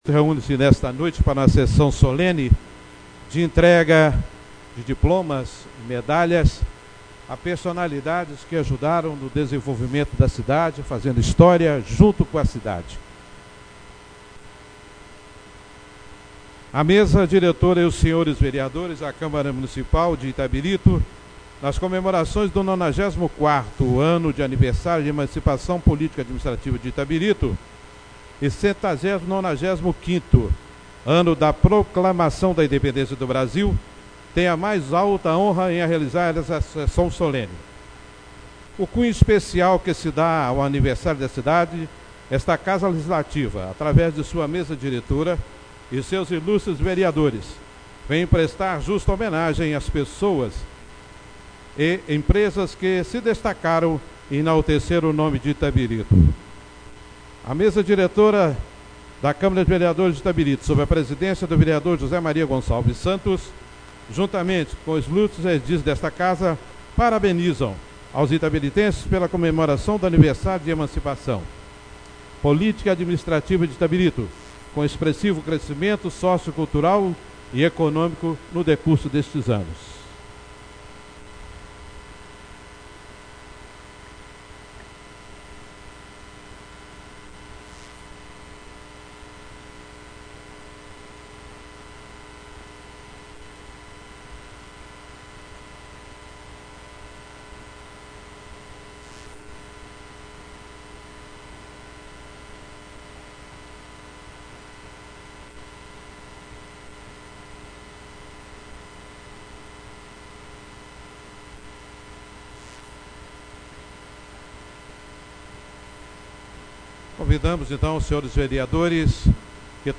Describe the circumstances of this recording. Sessão Solene e Homenagens do dia 11/09/2017